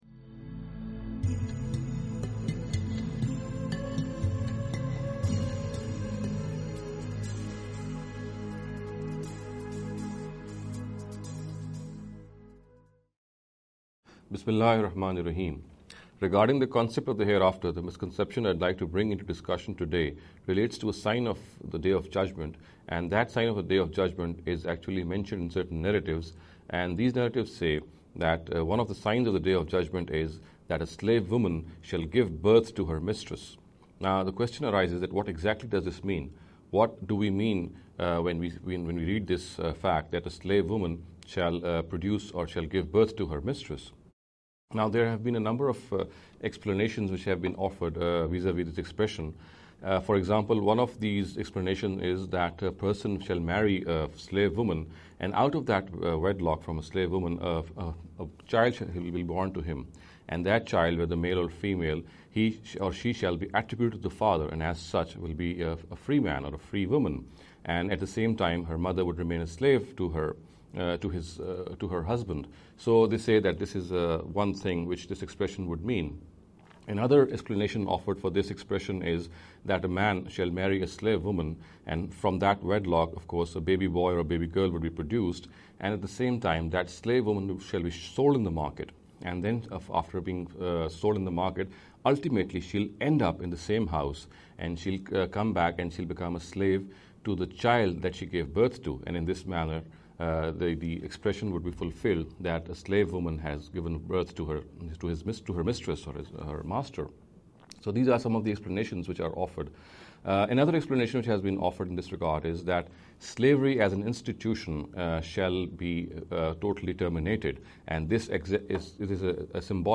This lecture series will deal with some misconception regarding the Concept of the Hereafter.